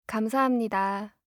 알림음 8_감사합니다2-여자.mp3